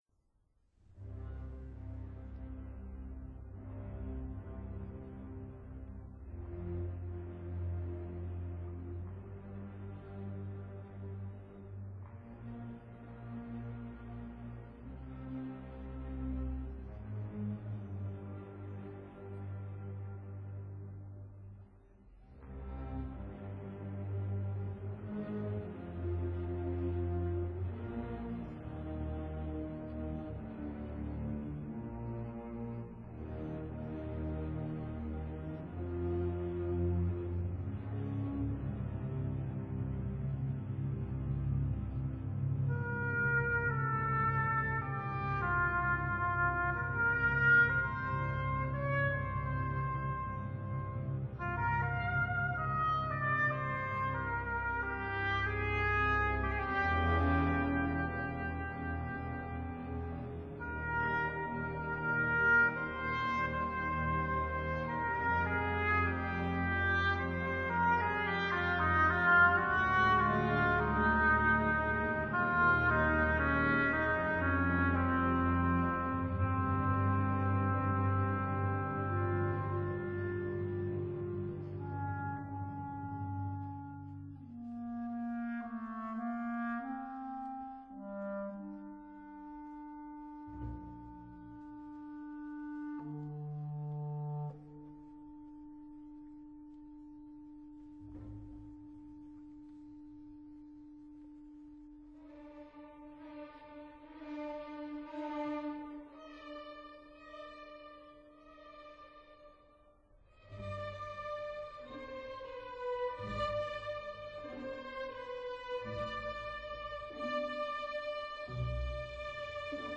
in A minor